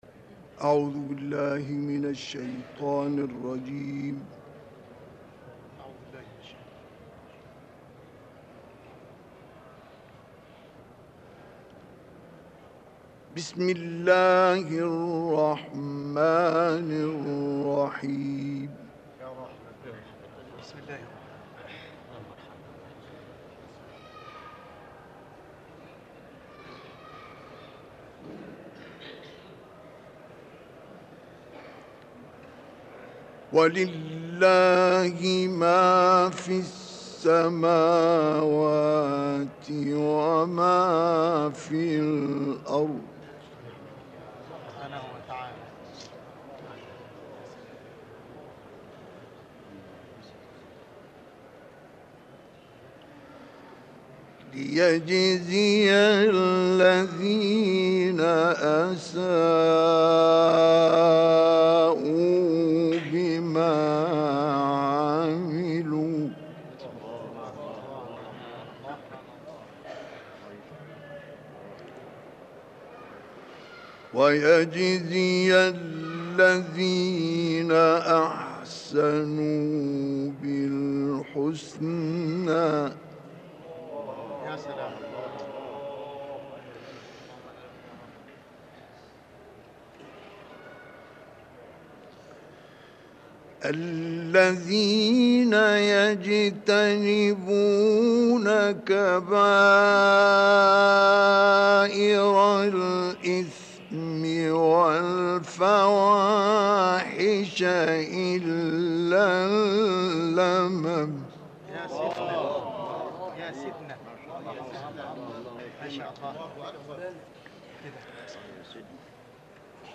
تهران - الکوثر : تلاوت سوریه های نجم ، قمر و طارق توسط استاد شیخ مصطفی اسماعیل قاری بزرگ مصری .